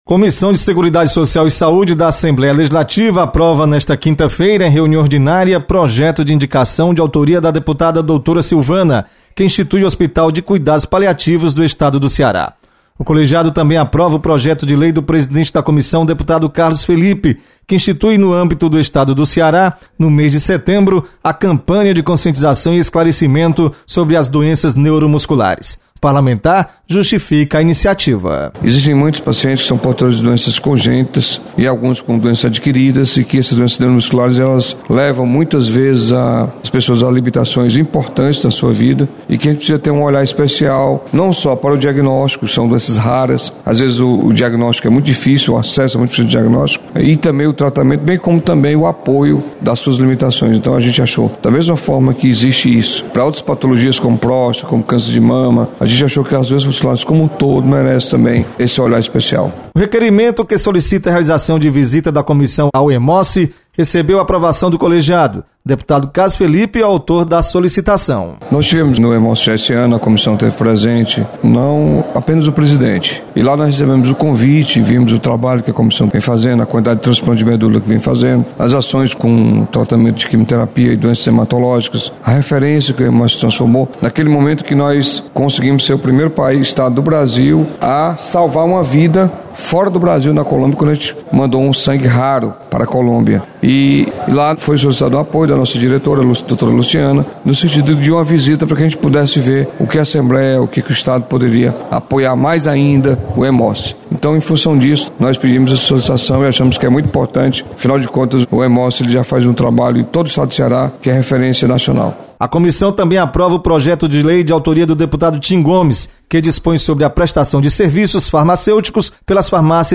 Aprovado projeto que institui cuidados paliativos. Repórter